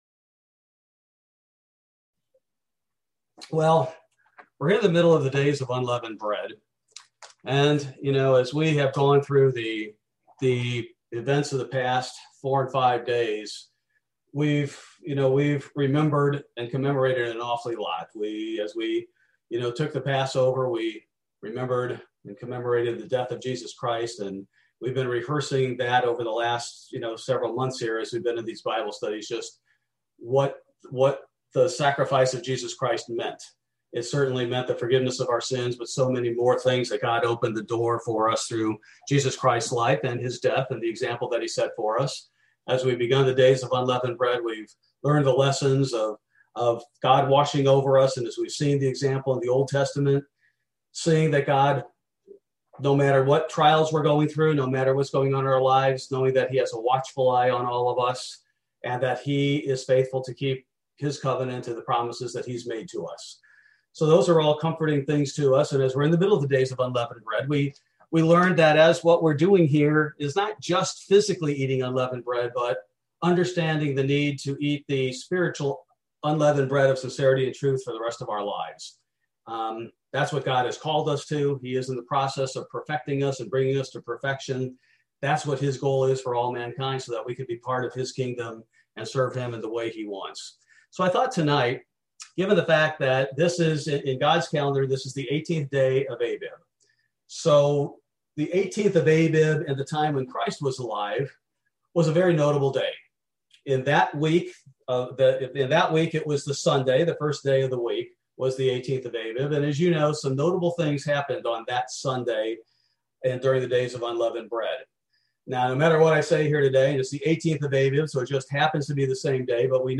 Bible Study: March 31, 2021